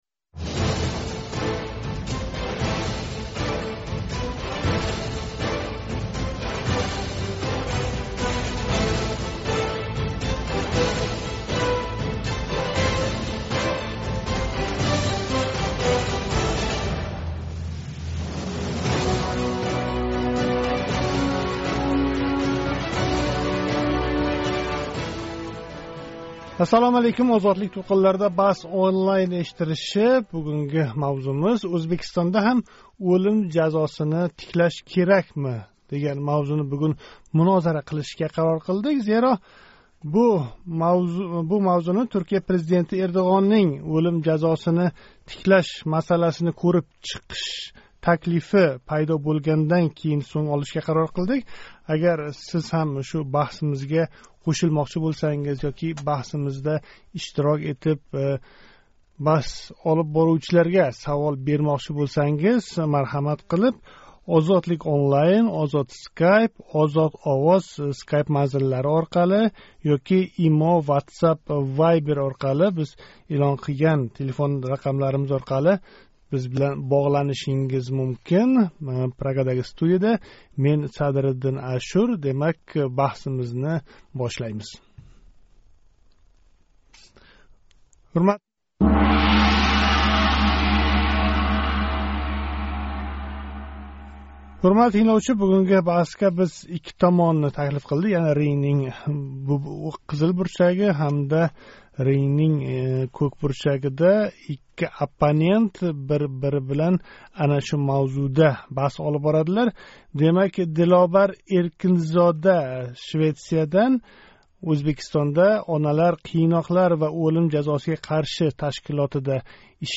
BahsOnlineнинг бу галги сони мавзуи Туркия президенти Эрдўғоннинг ўлим жазосини тиклаш масаласини кўриб чиқиш таклифидан пайдо бўлди. Агар сиз ҳам бу баҳсларга қўшилмоқчи бўлсангиз ҳар пайшанба куни Тошкент вақти билан соат 19.05дан кейин OzodlikOnline, OzodSkype, OzodOvoz Скайп манзиллари ҳамда IMO, WhatsApp, VIBER орқали боғланинг.